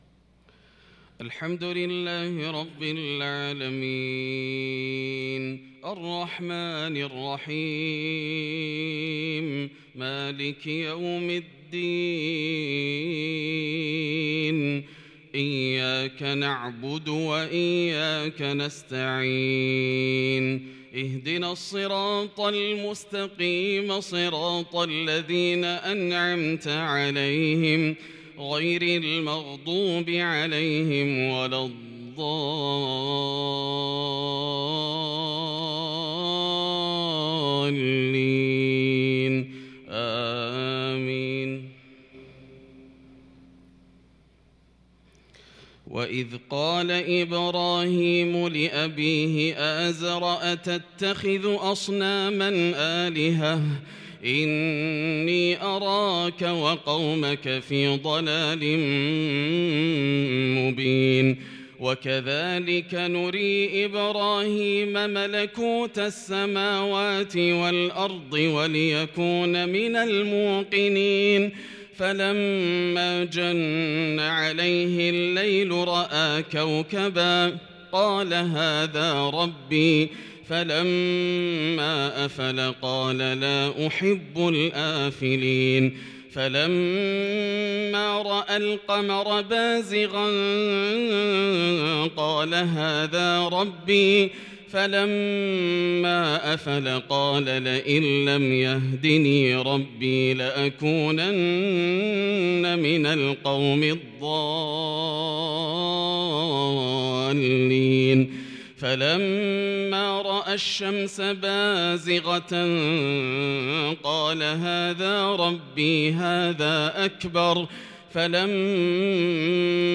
صلاة العشاء للقارئ ياسر الدوسري 12 محرم 1443 هـ
تِلَاوَات الْحَرَمَيْن .